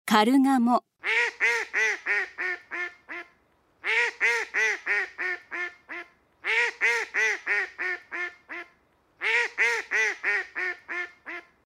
カルガモ
【鳴き声】普段は「グワッ」「グェッ」「ゲエ」と鳴き、オスは求愛の際に「ピィッ」と小さい声で鳴く。
カルガモの鳴き声（音楽：192KB）
karugamo.mp3